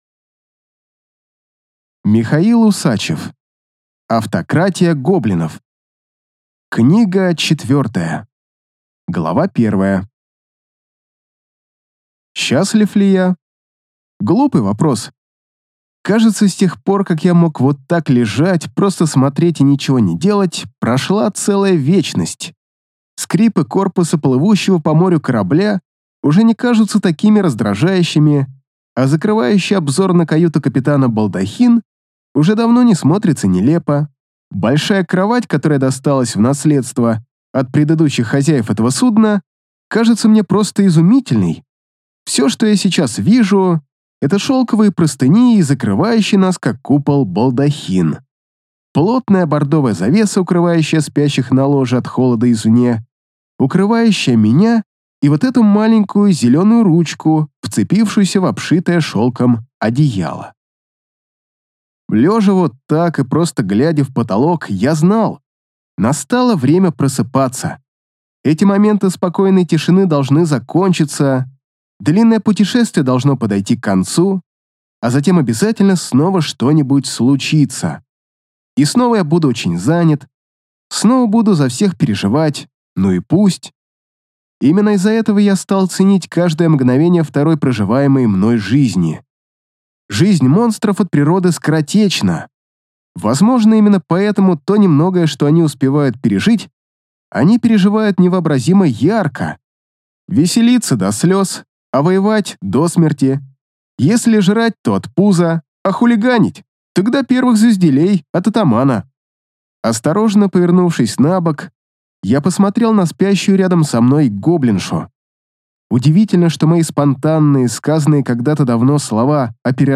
Аудиокнига Автократия гоблинов 4 | Библиотека аудиокниг